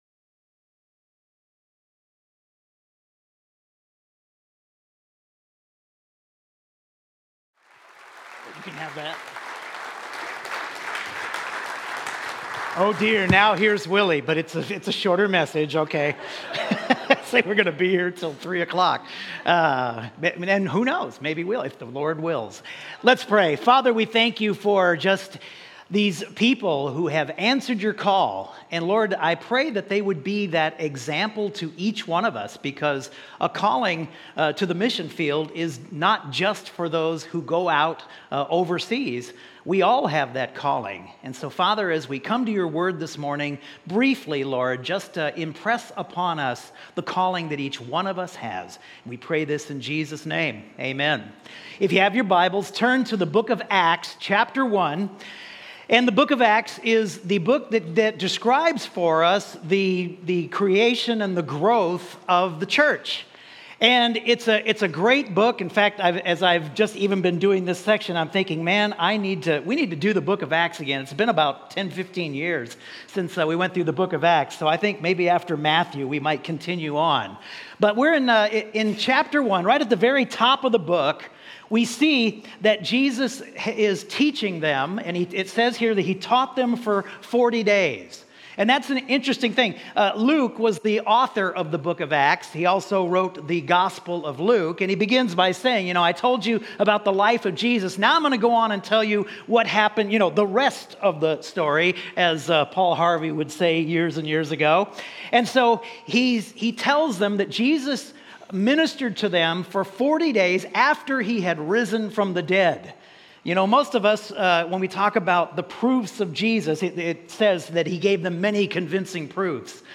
Sermons | CrossWinds Church
Today we’ll be hearing from a number of our supported Missionaries, both full-time and short-term.